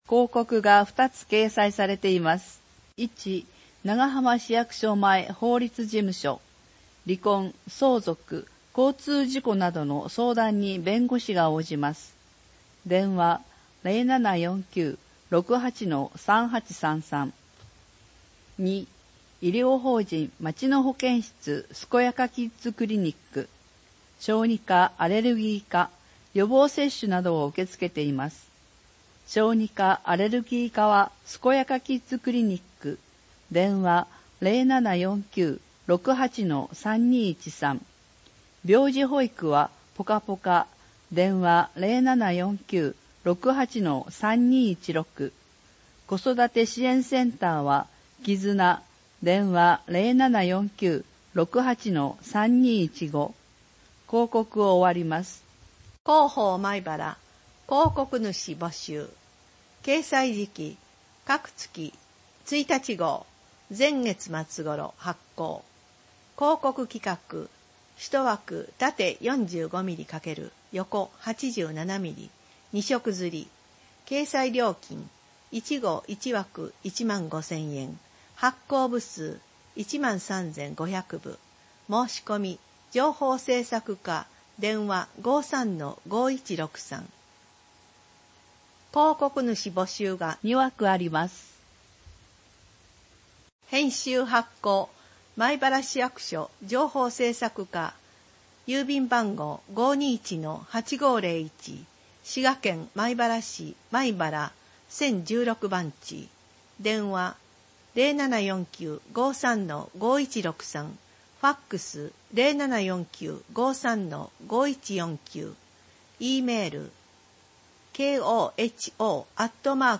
障がい者用に広報まいばらを音訳した音声データを掲載しています。
音声データは音訳グループのみなさんにご協力いただき作成しています。